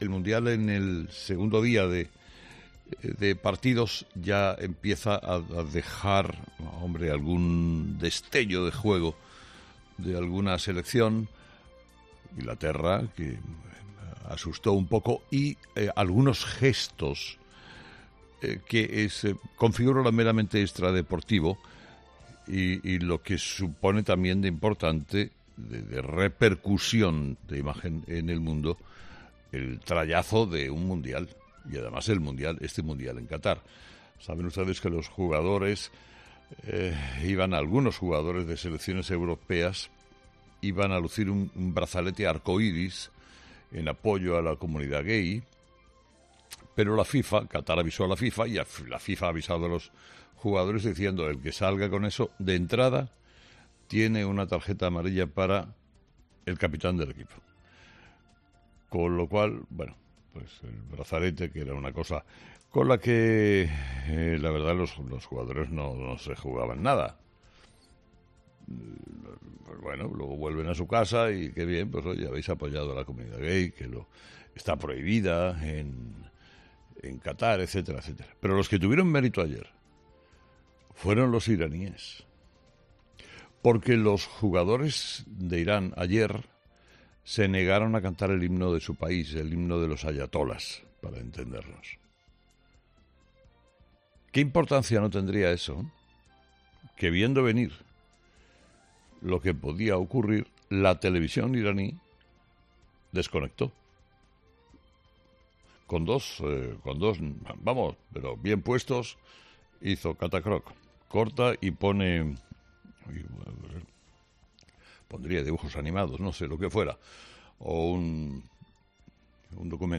Escucha a Carlos Herrera comparar el gesto de Irán con el de Inglaterra